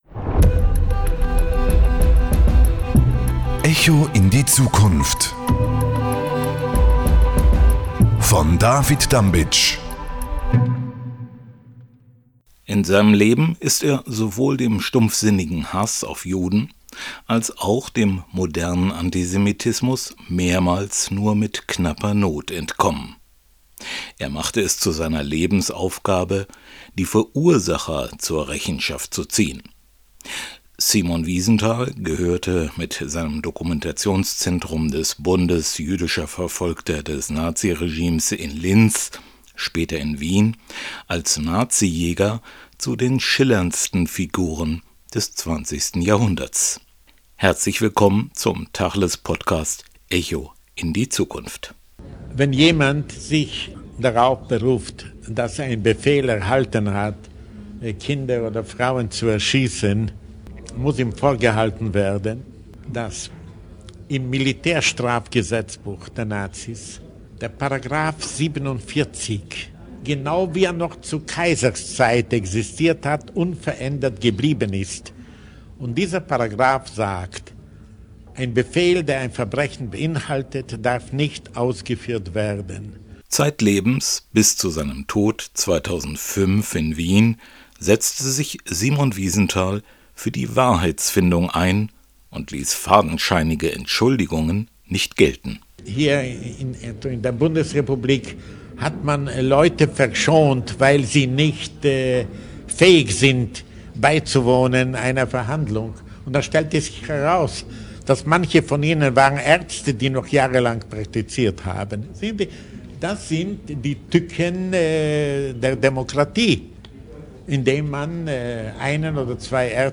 Simon Wiesenthal gehörte mit seinem Dokumentationszentrum des Bundes Jüdischer Verfolgter des Nazi-Regimes in Linz, später in Wien als Nazi-Jäger zu den schillerndsten Figuren des 20. Jahrhunderts. Die aktuelle Ausgabe des Podcast «Echo in die Zukunft» präsentiert ein spannendes Gespräch von 1992 über Wahrheitsfindung, die Notwendigkeit historischer Aufarbeitung und Gerechtigkeit.